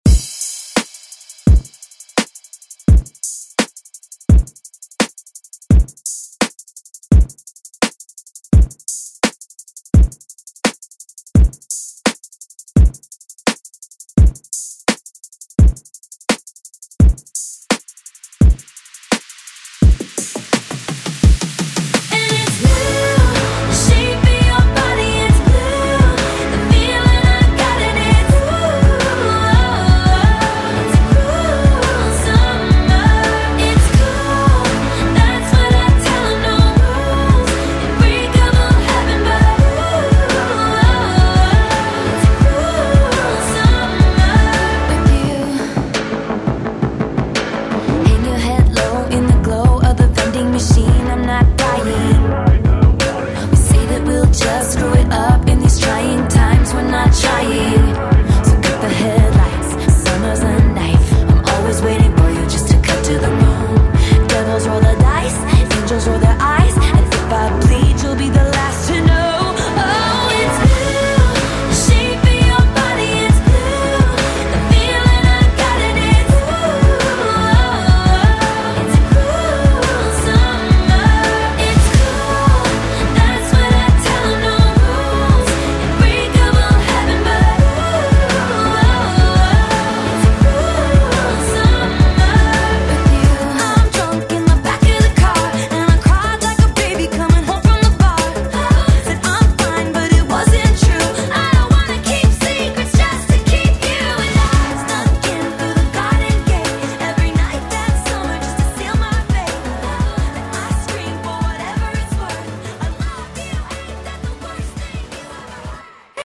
Genres: 2000's , HIPHOP , R & B Version: Dirty BPM: 90 Time